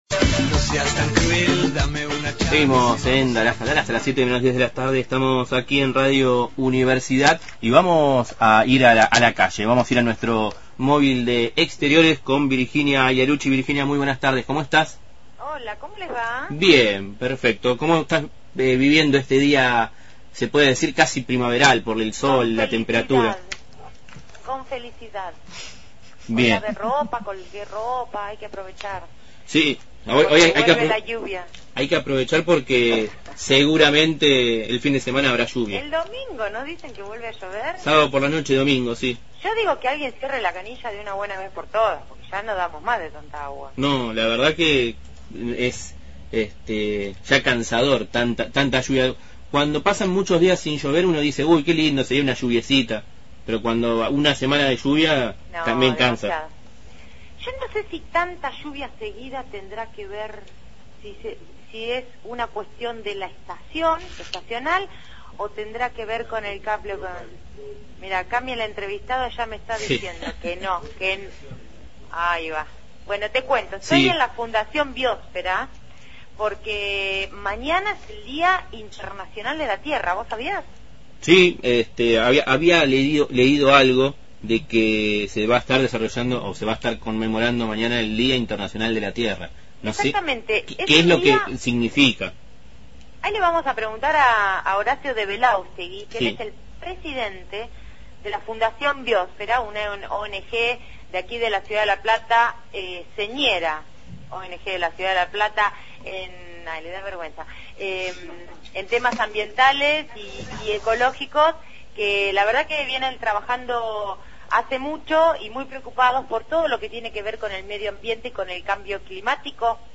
MÓVIL/ Día de la tierra – Radio Universidad